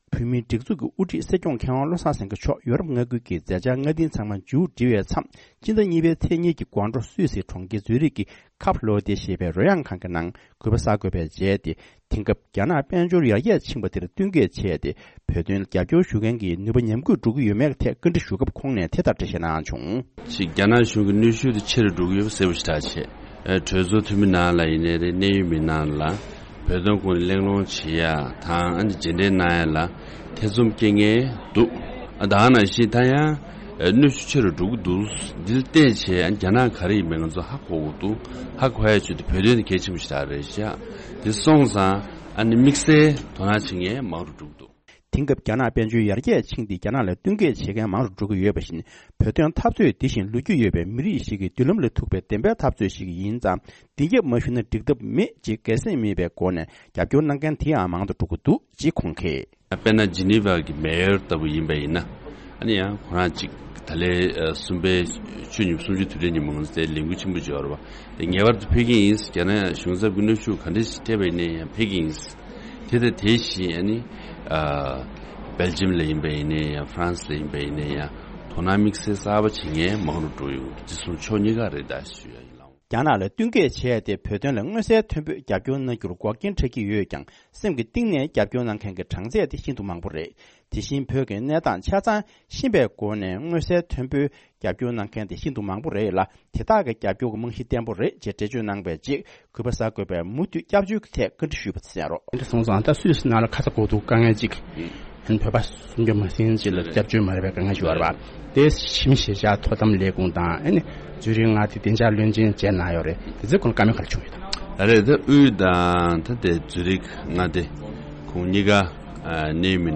འདི་གའི་གསར་འགོད་པས་སྲིད་སྐྱོང་མཆོག་ལ་དེང་སྐབས་རྒྱ་ནག་གི་དཔལ་འབྱོར་ཡར་རྒྱས་ཕྱིན་པ་དེར་བསྟུན་མཁས་བྱས་ཏེ་བོད་དོན་ལ་རྒྱབ་སྐྱོར་གནང་མཁན་གྱི་ནུས་པ་ཉམས་ར྄ྒུད་འགྲོ་ཡོད་མེད་བཀའ་འདྲི་ཞུས་པ།